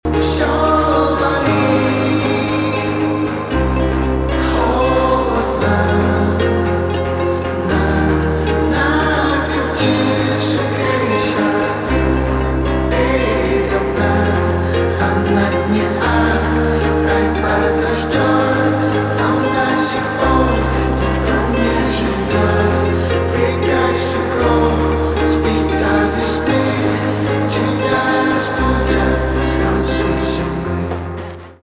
грустные , кайфовые , тихие , спокойные
гитара